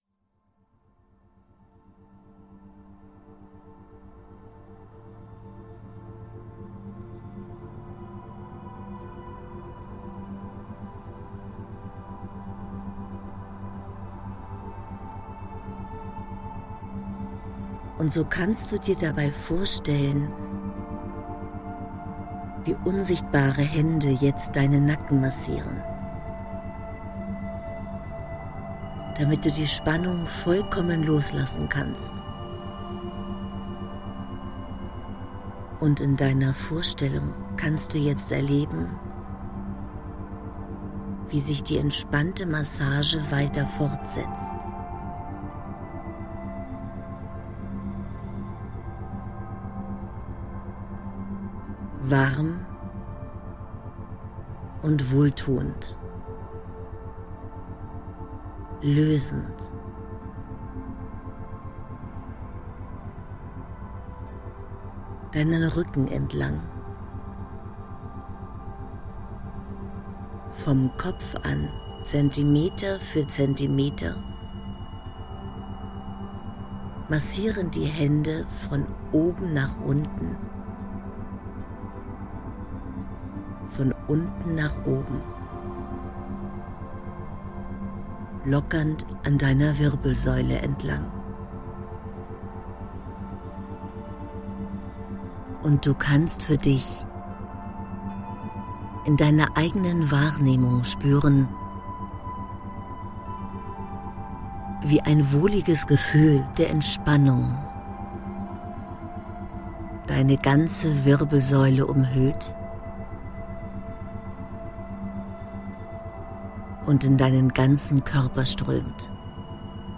Aktivierung der Selbstheilungskräfte & Regeneration – Dieses Hörbuch hilft Ihnen sich vom Stress zu befreien und sich körperlich und geistig zu erholen.
Durch die spezielle neurologische Hintergrundmusik Vivaflow Brainwaves werden ihre Gehirnwellen ganz natürlich stimuliert.
Jede Anwendung ist ein harmonisches Zusammenspiel von inspirierenden Texten, bewegenden Stimmen und sanft stimulierender Begleitmusik.